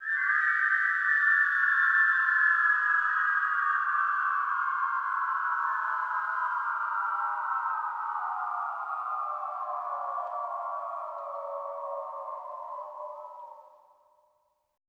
WH CL FALL-R.wav